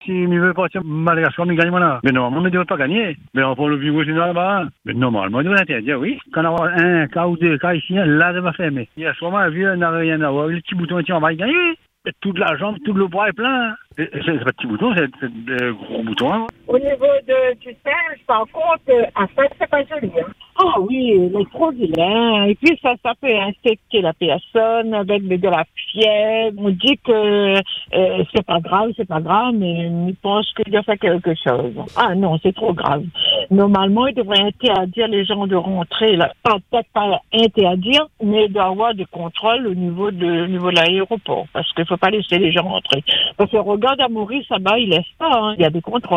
Sur l’antenne, certains auditeurs s’interrogent :